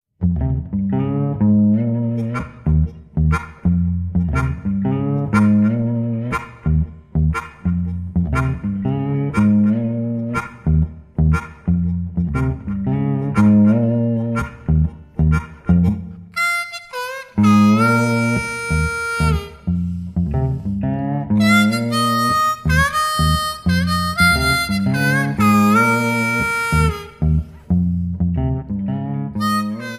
Lap Slide Steel Guitar
Harmonica
A lap slide guitar and a harmonica.
” -a beguiling melody- are tall tales.
an instrumental blues duet